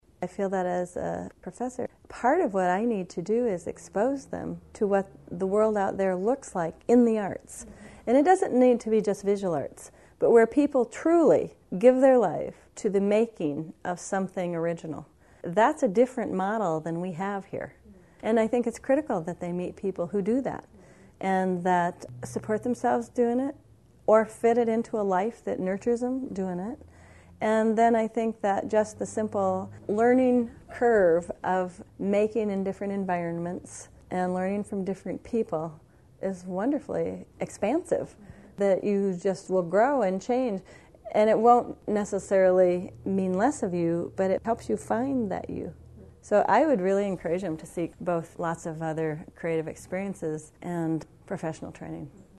The Iowa Women Artists Oral History Project records and preserves the voices of women visual artists in Iowa reflecting on their lives and their artwork.